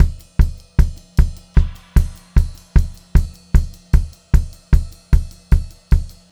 152SPCYMB3-R.wav